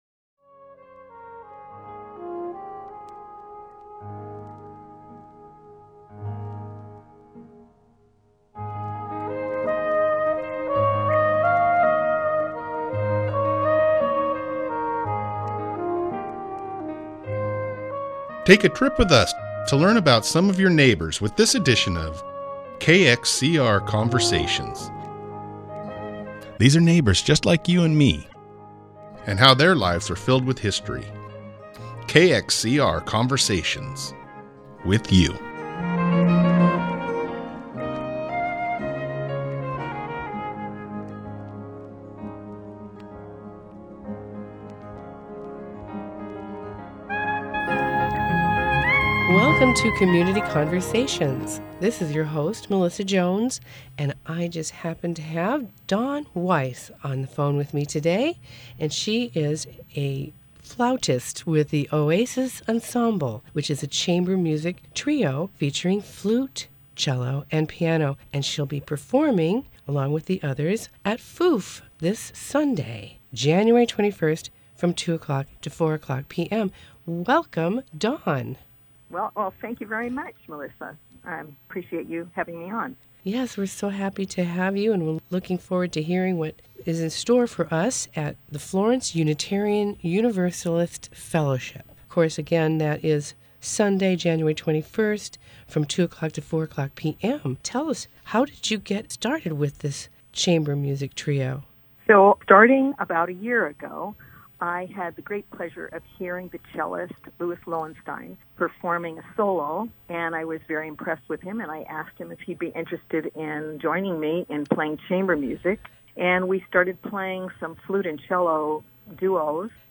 Listen to our 30 minute KXCR Community Radio Station in Florence, Oregon interview, on January 21st, 2024.